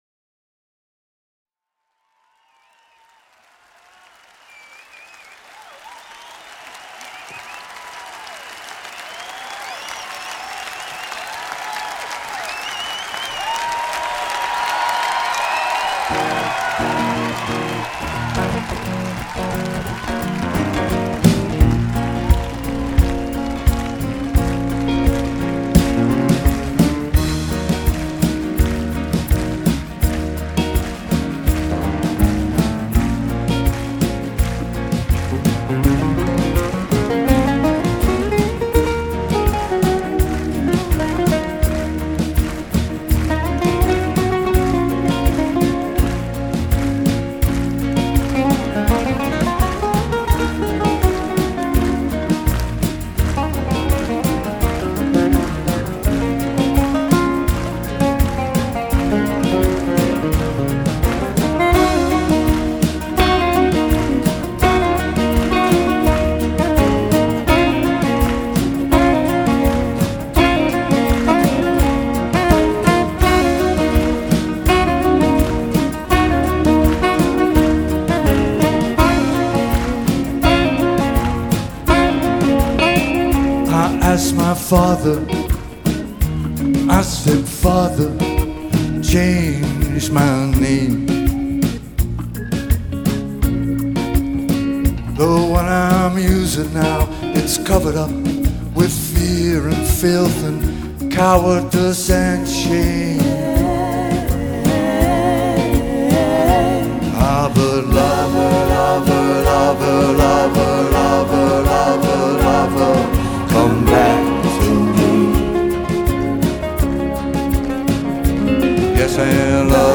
Live Sept 24